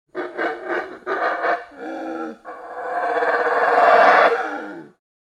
Monkey Growling Aggressive Botão de Som